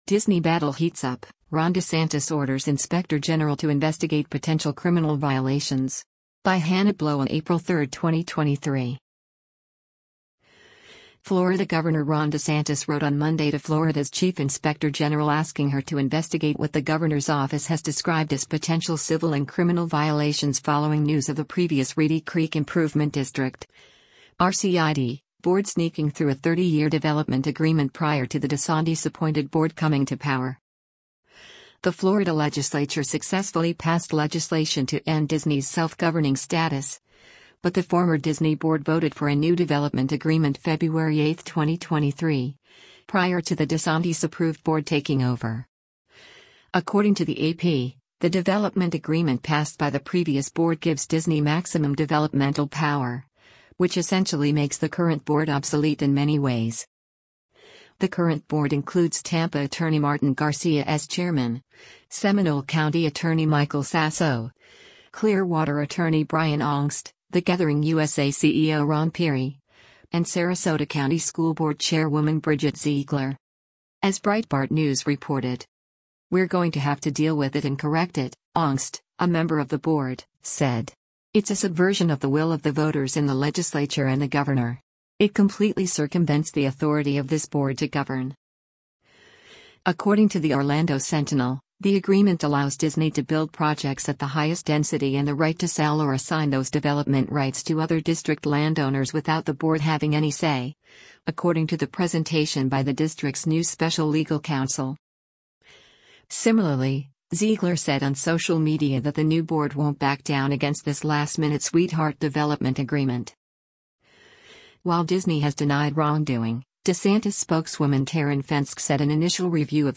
SURFSIDE, FLORIDA - JUNE 14: Florida Gov. Ron DeSantis speaks during a press conference at
WATCH: Ron Desantis Announces Florida Lawmakers Will Consider Terminating Special Districts, Including Disney’s Reedy Creek Improvement District